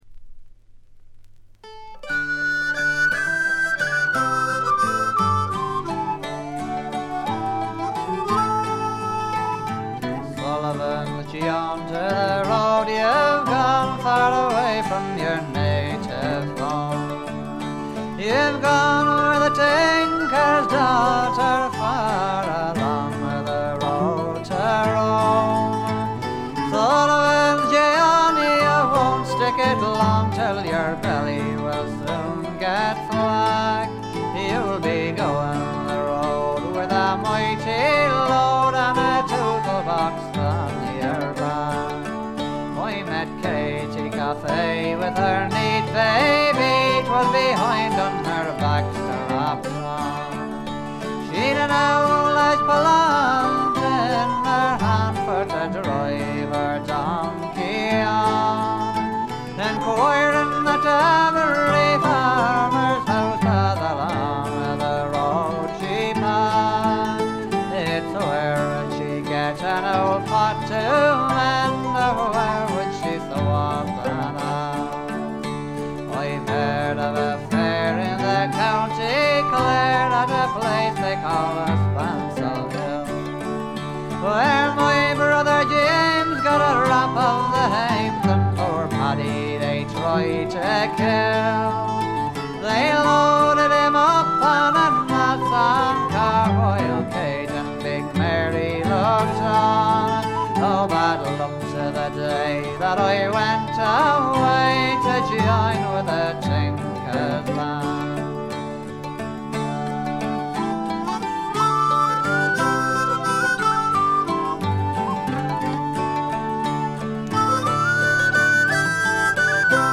散発的なプツ音2回ほど。
試聴曲は現品からの取り込み音源です。
Vocals, Bouzouki, Tin Whistle
Vocals, Guitar, Banjo, Concertina
Vocals, Mandolin, Harmonica, Guitar